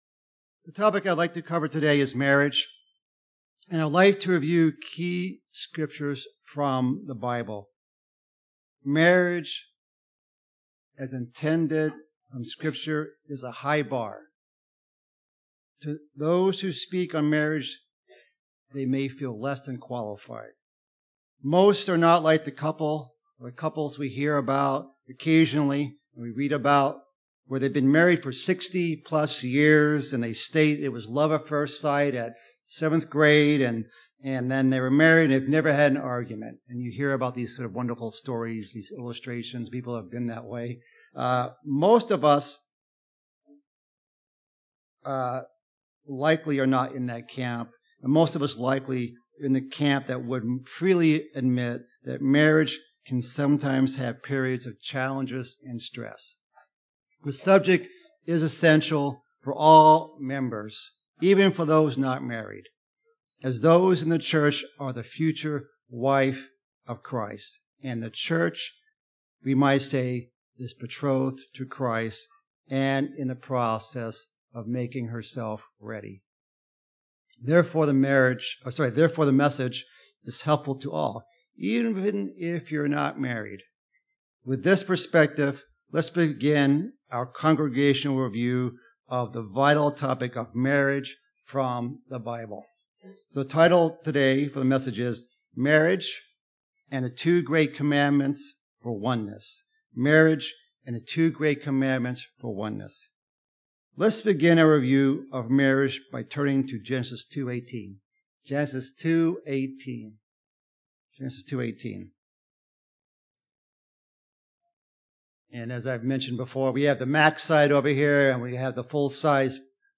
God designed marriage to be a model of the relationship between Christ and the church. This sermon identifies Biblical principles of how a godly couple can "become one."